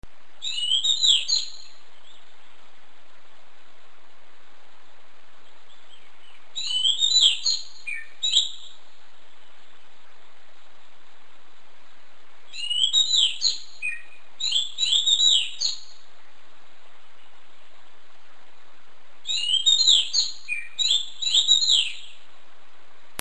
Unknown bird sound for id
"I attach a bird sound for yours to identify, it sounds a little bit like a Magpie Robin.
I attach one more sound in mp3 format for your reference, it's a little bit different with the previous one, but again, she wakes me up again at 4:30am, I am  going out again and only watch this small bird fly away.